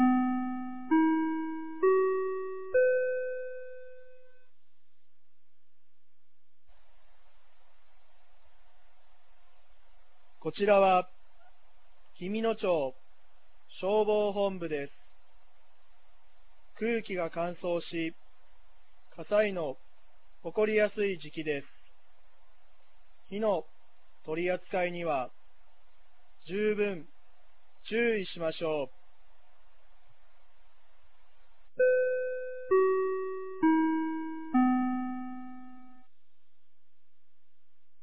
2025年11月08日 16時00分に、紀美野町より全地区へ放送がありました。